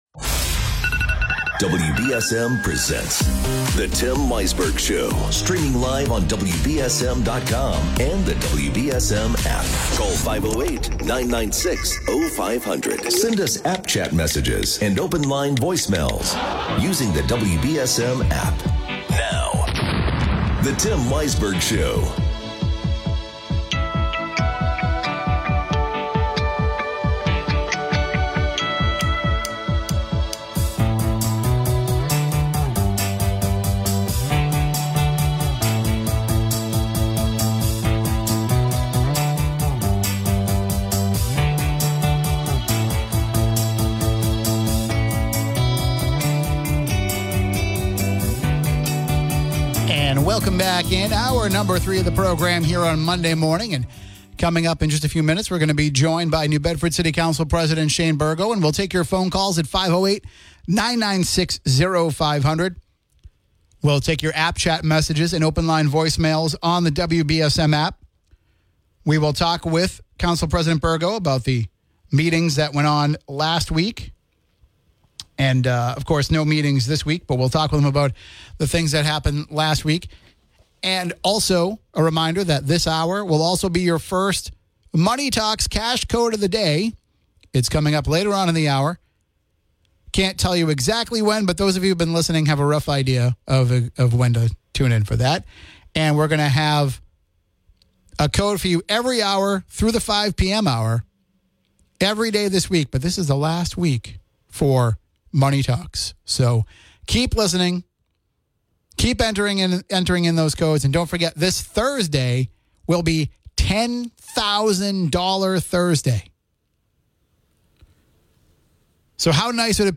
… continue reading 101 epizódok # News # 1420 WBSM # Daily News # Townsquare Media # Tims Talking